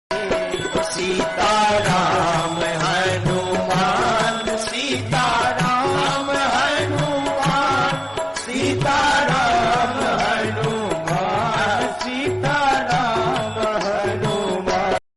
• Quality: High / Clear Audio
• Category: Devotional / Bhajan Ringtone